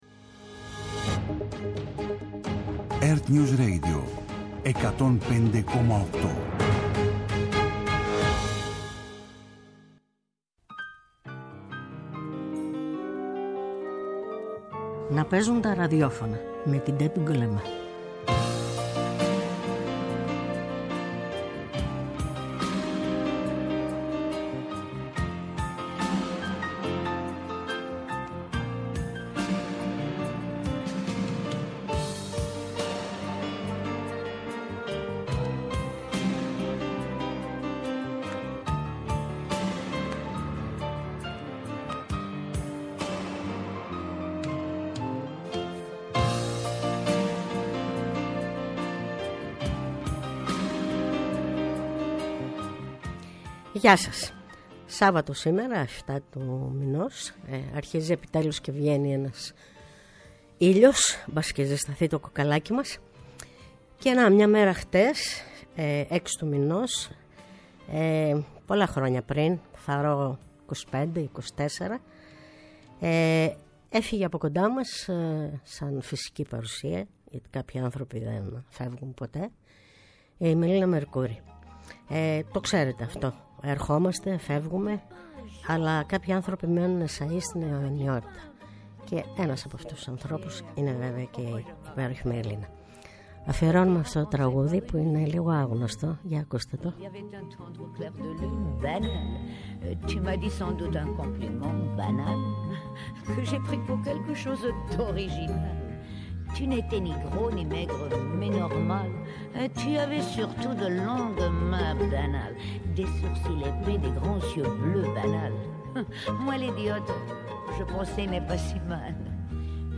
φιλοξενεί στο στούντιο ανθρώπους της Τέχνης -και όχι μόνο- σε ενδιαφέρουσες συζητήσεις με εξομολογητική και χαλαρή διάθεση. ΕΡΤNEWS RADIO